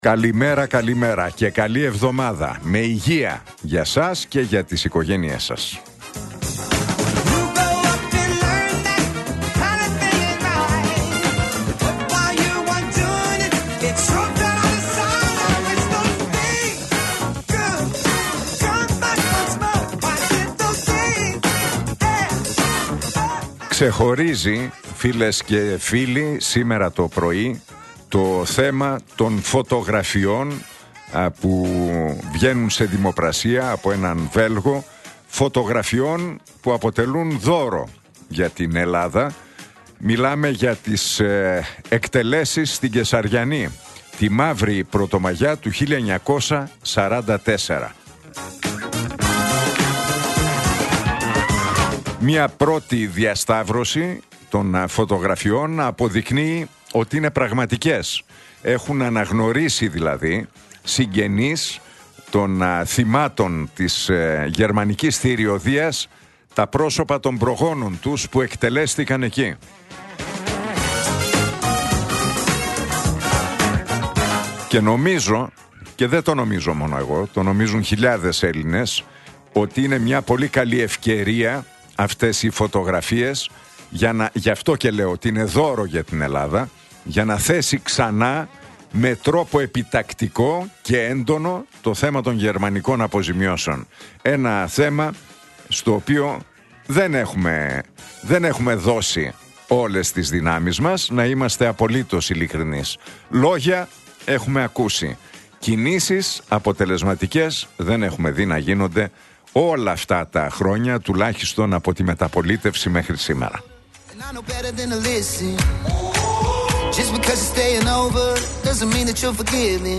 Ακούστε το σχόλιο του Νίκου Χατζηνικολάου στον ραδιοφωνικό σταθμό Realfm 97,8, τη Δευτέρα 16 Φεβρουαρίου 2026.